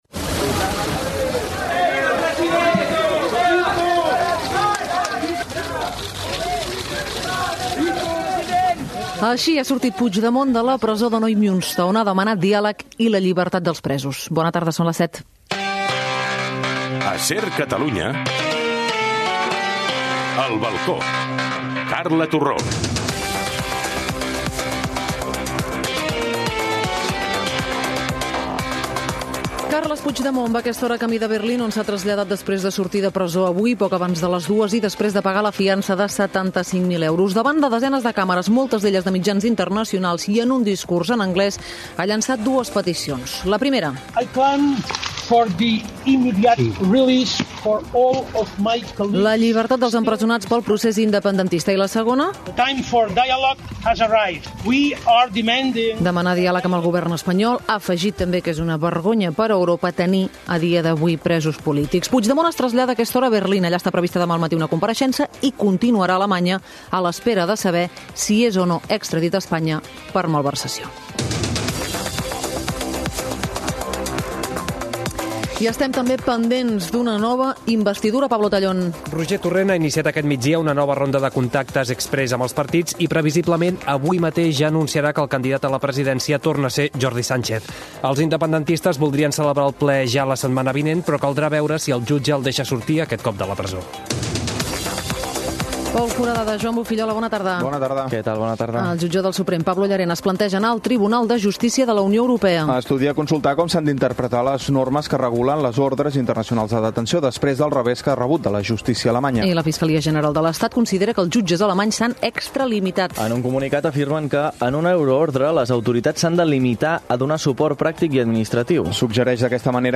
Hora, indicatiu del programa, sumari informatiu, el temps,els esports, hora, informació de la sortida de la presó de Neumünster (nord d'Alemanya) del president de la Generalitat destituït i a l'exili Carles Puigdemont
Informatiu